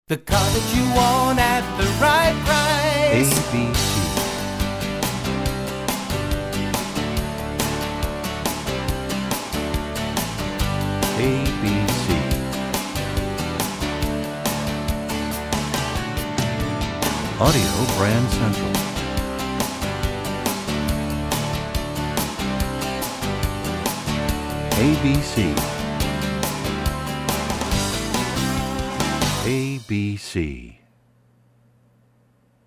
MCM Category: Ad Jingles